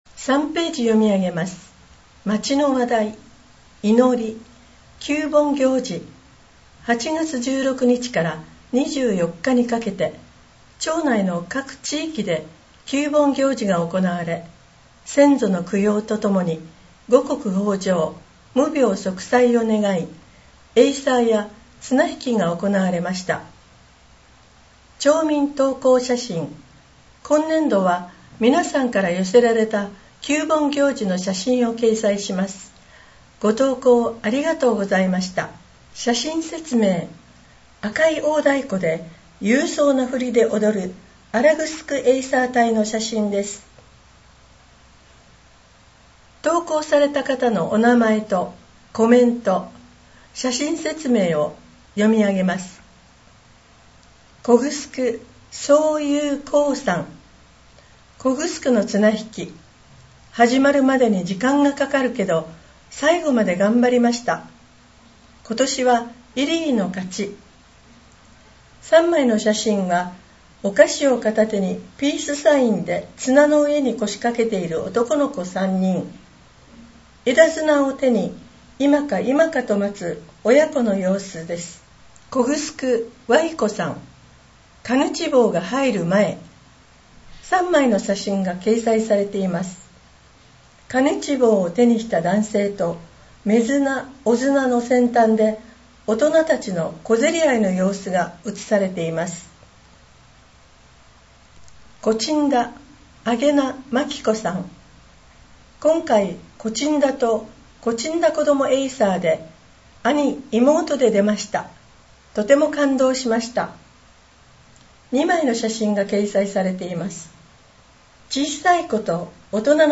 声の「広報やえせ」　令和6年10月号226号